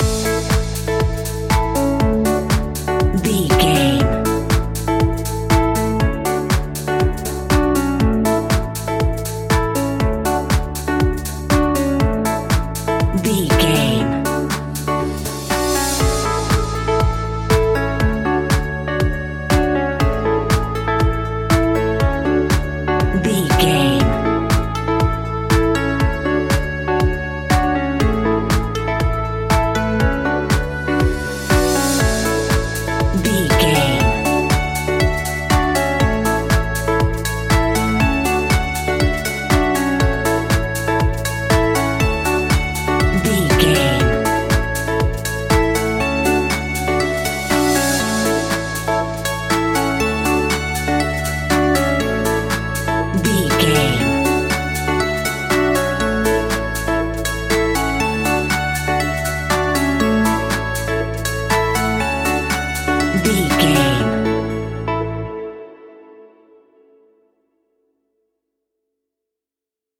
Ionian/Major
groovy
energetic
uplifting
hypnotic
electric guitar
bass guitar
drum machine
synthesiser
funky house
deep house
nu disco
wah clavinet